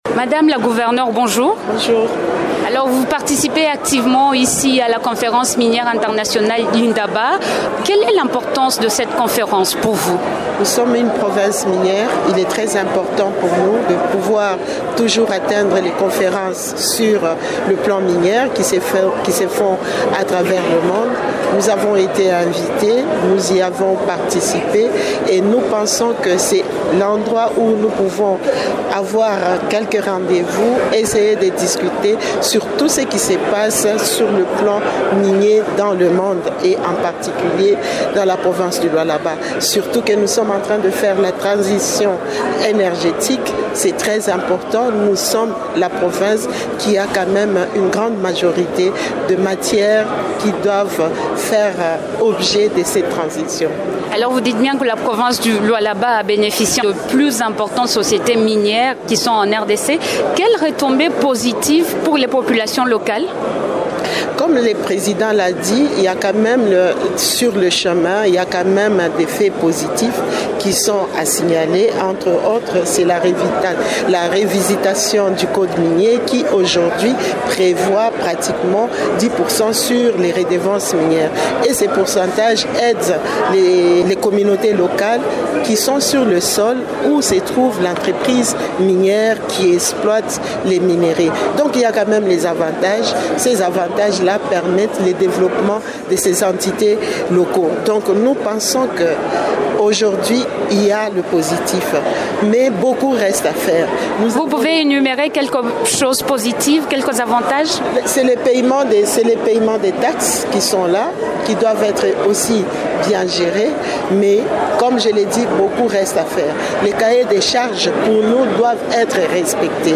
Invitée de Radio Okapi, elle indique que cette rencontre offre l’opportunité à la RDC de mieux se vendre sur le plan minier.